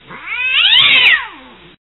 دانلود صدای جیغ گربه از ساعد نیوز با لینک مستقیم و کیفیت بالا
جلوه های صوتی